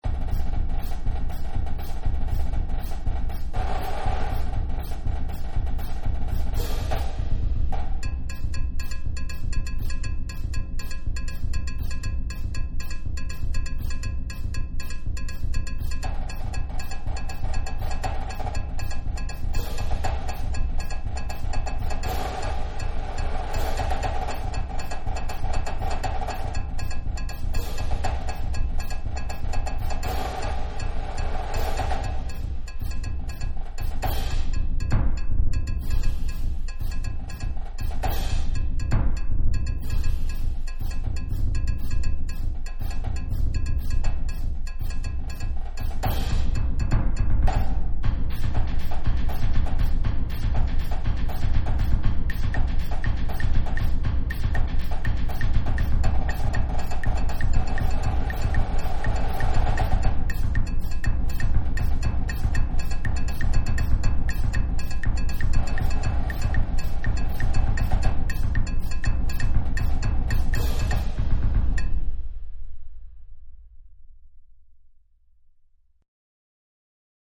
Ongestemd Marcherend Slagwerk
Snare drum Cowbell Claves Cymbals Bass drum